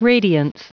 Prononciation du mot : radiance
radiance.wav